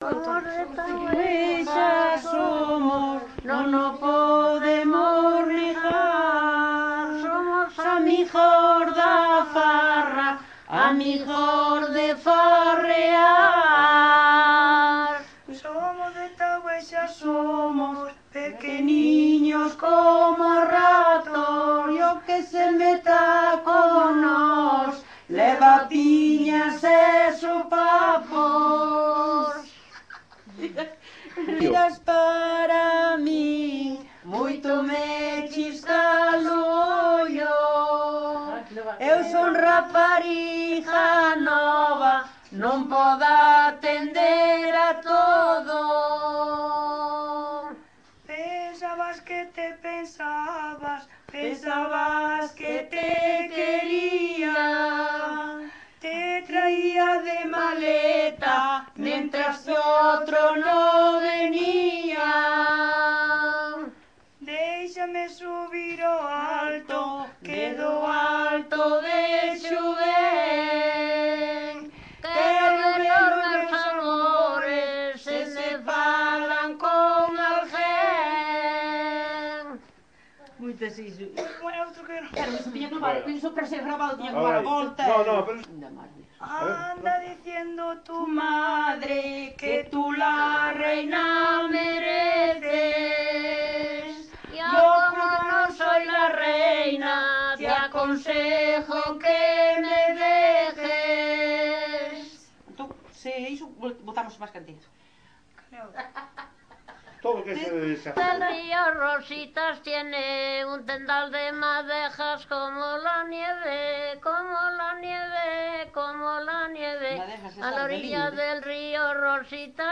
Palabras chave: coplas xota jota muiñeira
Tipo de rexistro: Musical
Soporte orixinal: Casete
Xénero: Xota, Muiñeira
Instrumentación: Voz
Instrumentos: Voces femininas